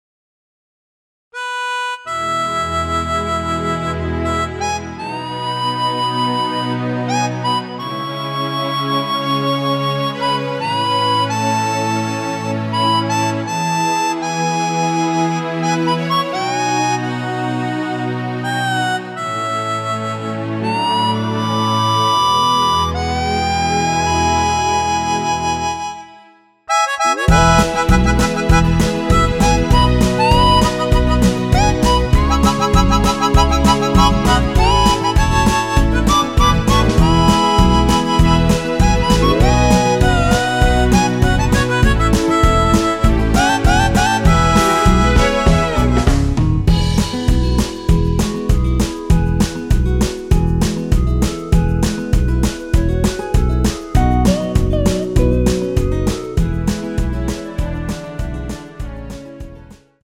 원키에서(+4)올린 멜로디 포함된 MR입니다.(미리듣기 확인)
◈ 곡명 옆 (-1)은 반음 내림, (+1)은 반음 올림 입니다.
멜로디 MR이라고 합니다.
앞부분30초, 뒷부분30초씩 편집해서 올려 드리고 있습니다.
중간에 음이 끈어지고 다시 나오는 이유는